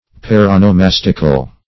Search Result for " paronomastical" : The Collaborative International Dictionary of English v.0.48: Paronomastic \Par`o*no*mas"tic\, Paronomastical \Par`o*no*mas"tic*al\, a. Of or pertaining to paronomasia; consisting in a play upon words.